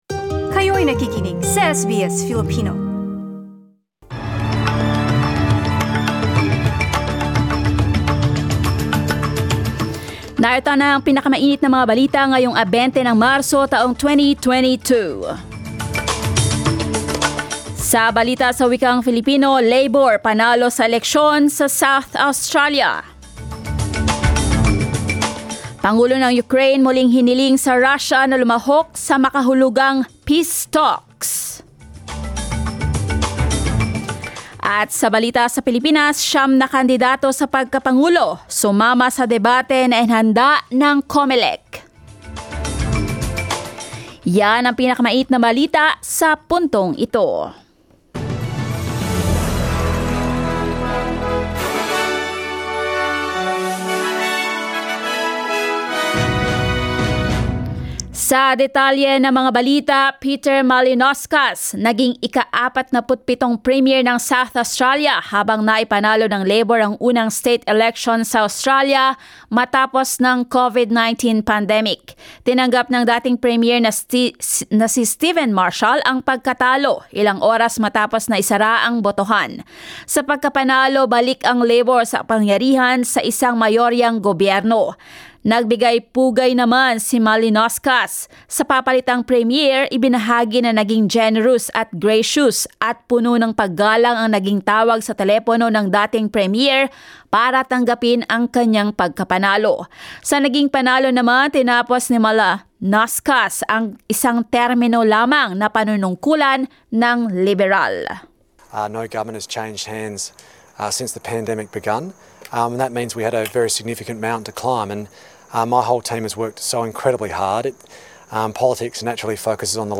SBS News in Filipino, Sunday 20 March